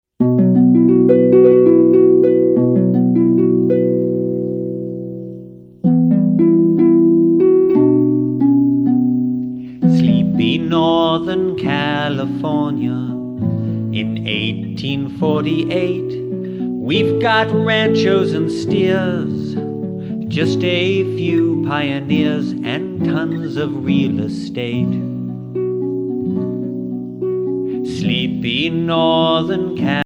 A Social Studies Musical
*  Catchy melodies, dumb jokes, interesting stories